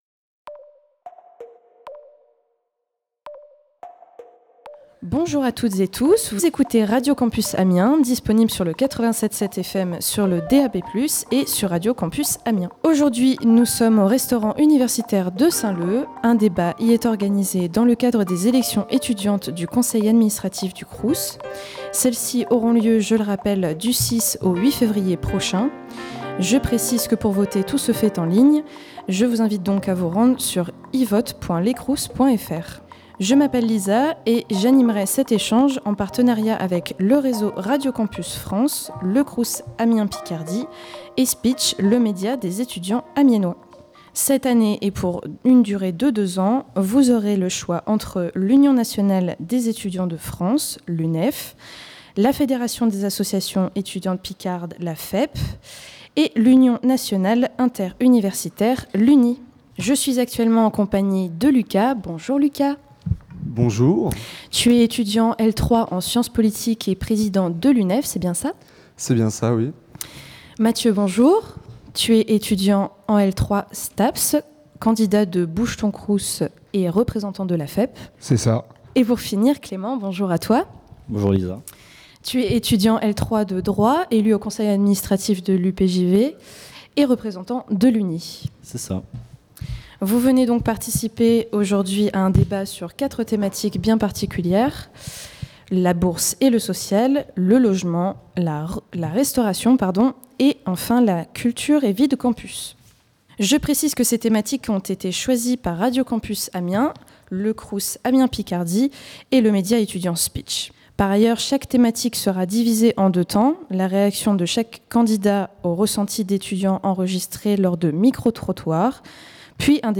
Dans le cadre des élections des représentants étudiants au Conseil d’Administration du CROUS Amiens Picardie, qui auront lieu du 6 au 8 février prochain, Radio Campus Amiens a animé un débat entre les différents candidats le jeudi 25 janvier 2024 au restaurant universitaire de Saint-Leu.